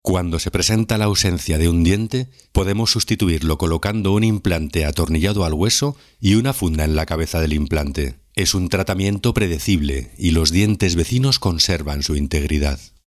Mi voz es grave, profunda, definida, elegante y clara.
Registro barítono incluso bajo.
Sprechprobe: eLearning (Muttersprache):
My voice is deep, defined, elegant and clear.